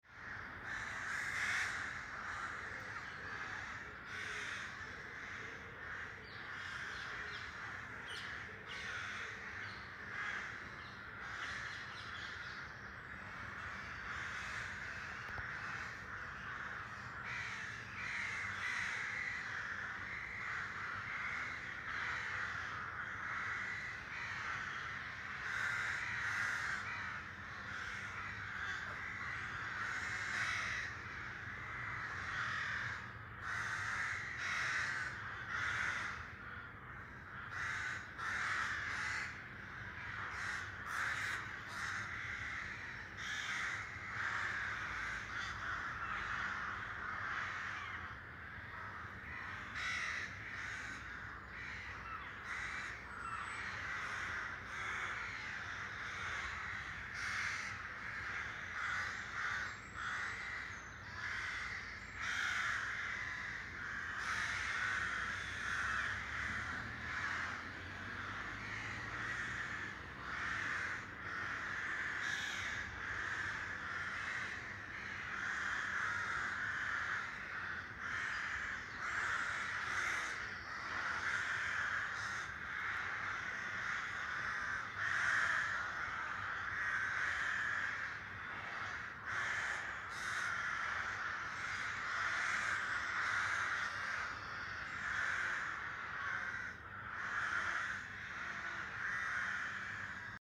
Звук грача скачать
4. Звуки стаи грачей на деревьях в городском парке создают шум
grachi-park.mp3